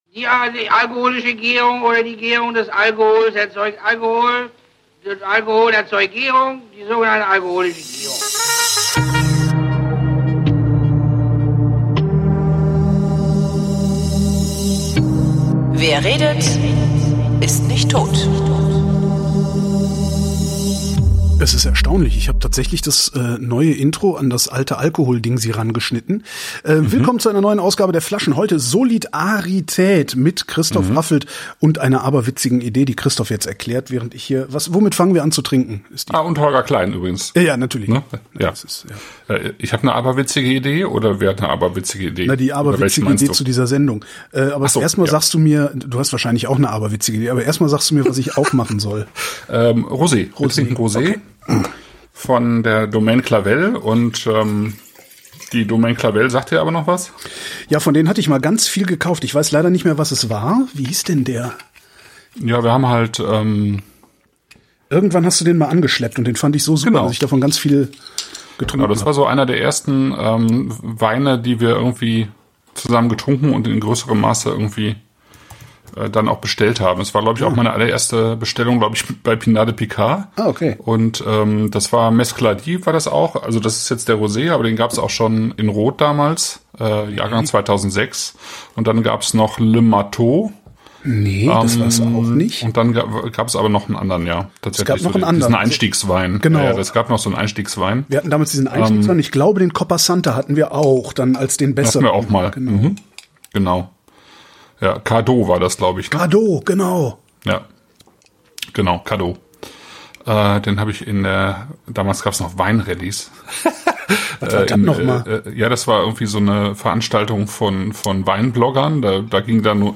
Das Audio hatte starke Lautstärkeunterschiede und ist jetzt (23.8.2021) repariert.